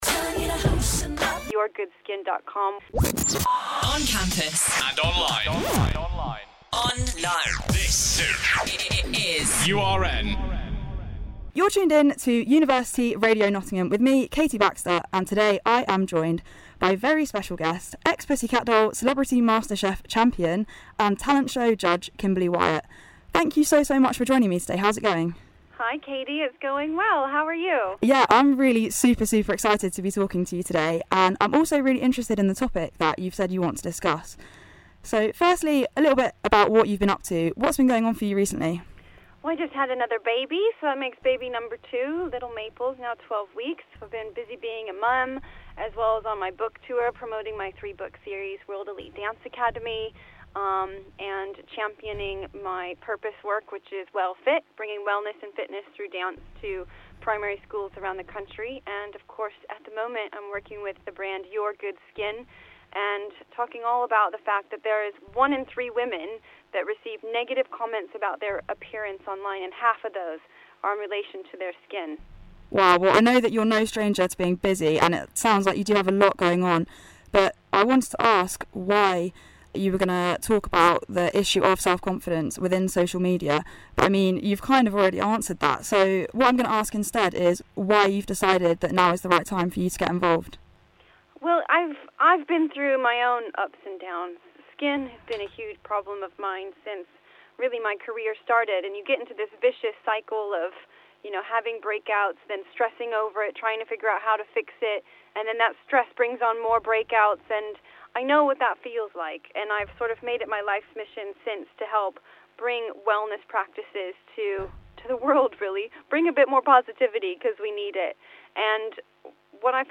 Kimberley Wyatt Interview